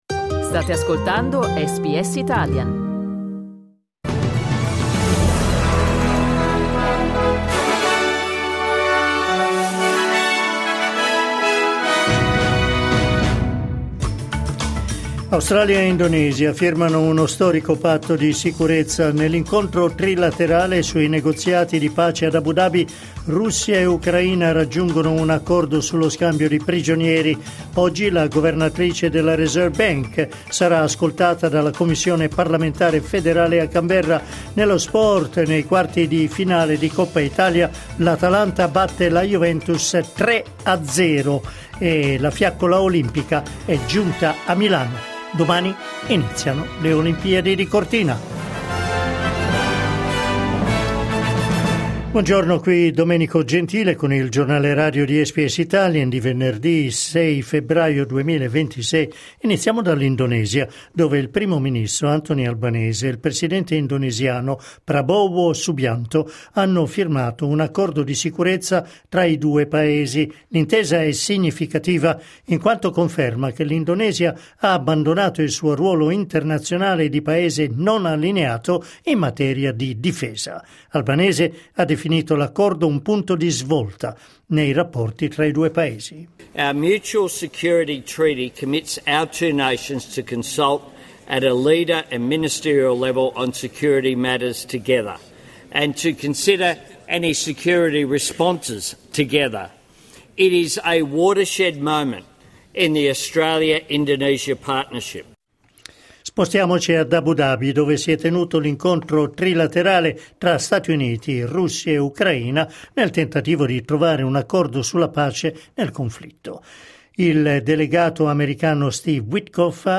Giornale radio venerdì 6 febbraio 2026
Il notiziario di SBS in italiano.